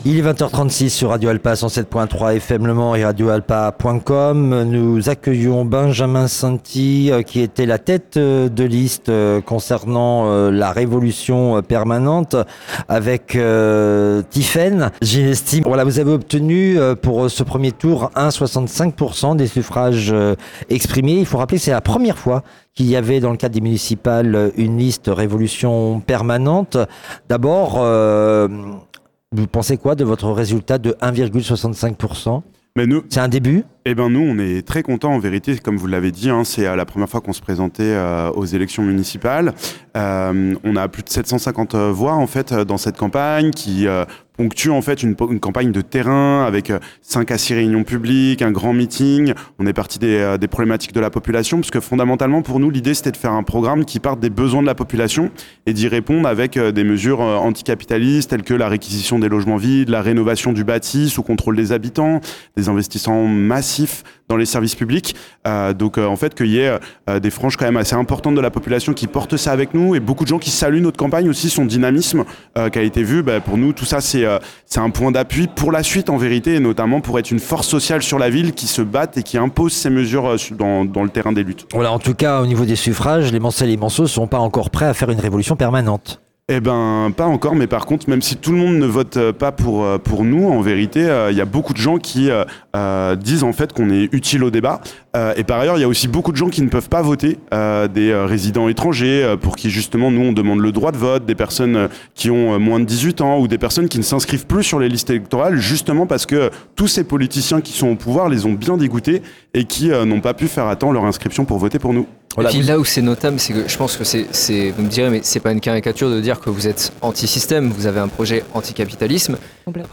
étaient présents à la Préfecture pour l’annonce des résultats du premier tour des élections municipales, au Mans et en Sarthe.
De 19h45 à 22h20, ils ont reçu plusieurs élus du territoire et la plupart des têtes de liste du Mans pour commenter et analyser les résultats.